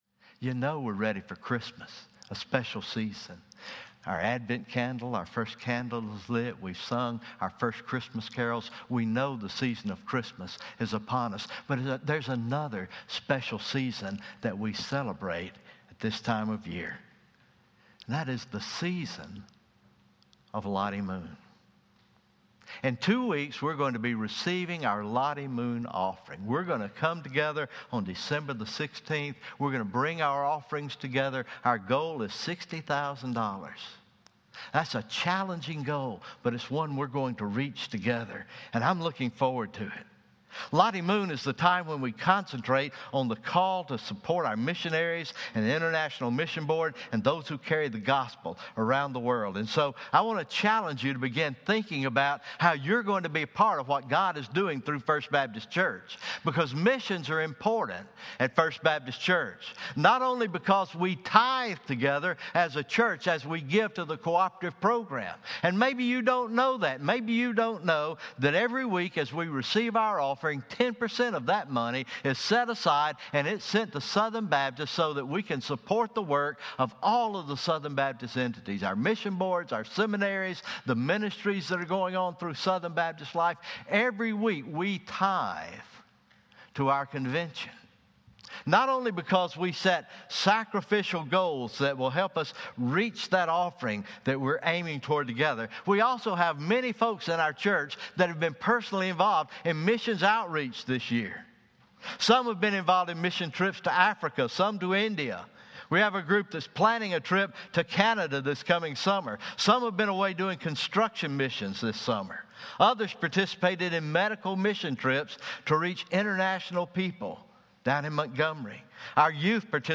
Sermon Recordings